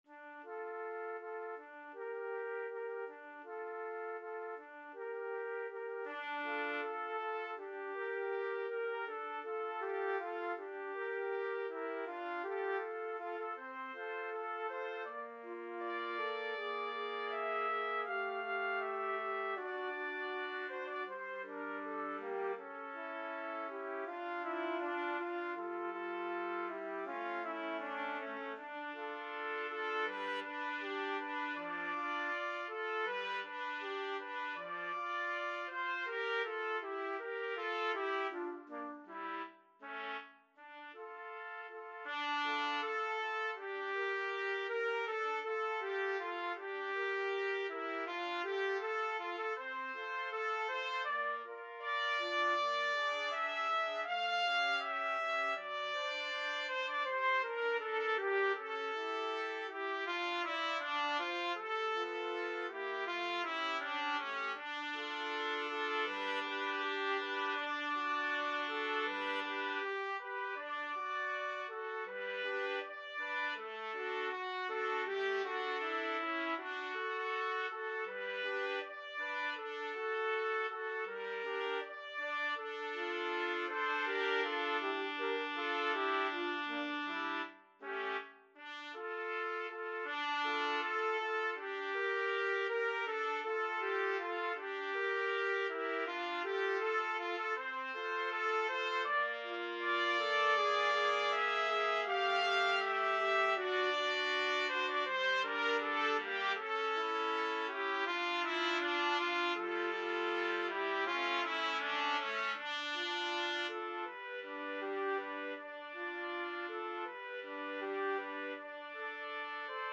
~ = 100 Andante
Classical (View more Classical Trumpet Trio Music)